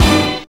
SWINGSTAB 8.wav